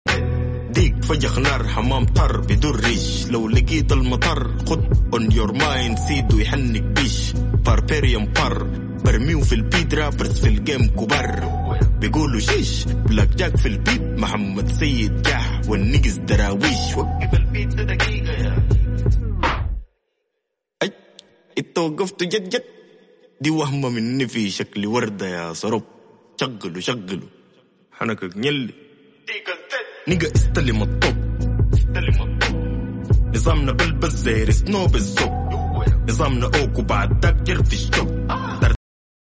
راب سوداني